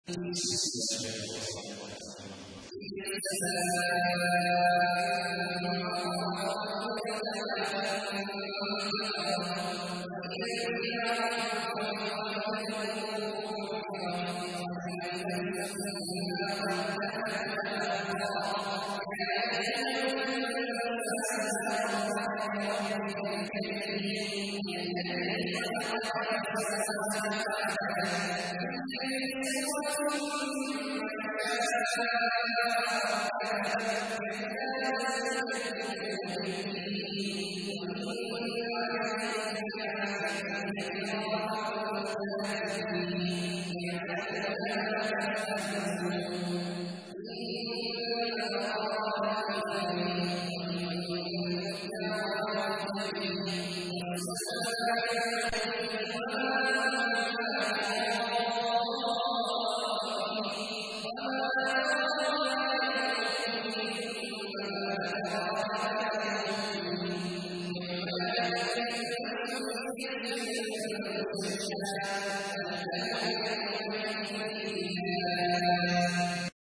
تحميل : 82. سورة الانفطار / القارئ عبد الله عواد الجهني / القرآن الكريم / موقع يا حسين